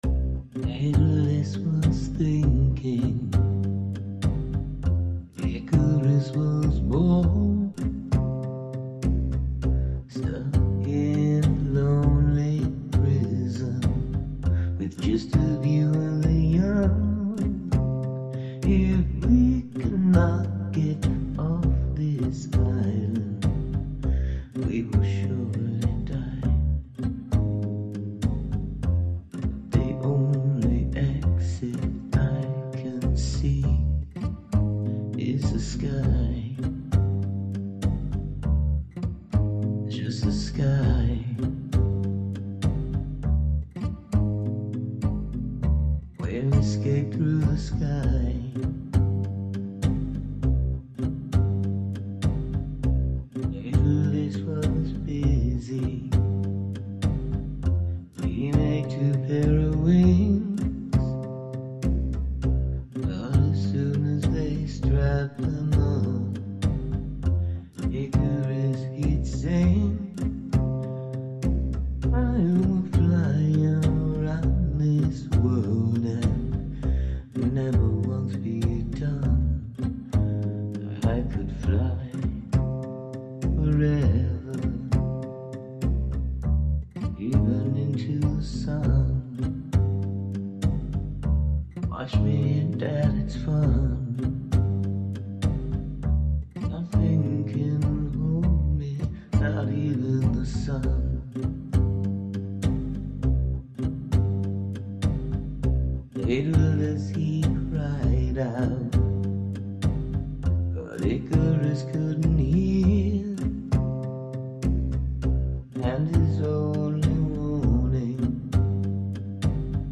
Moody and mysterious - your voice as well as the guitar.